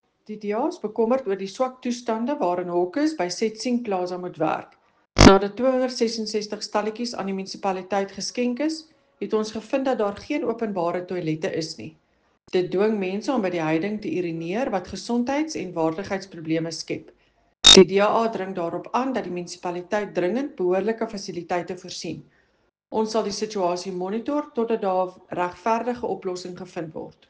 Afrikaans soundbite by Cllr Eleanor Quinta and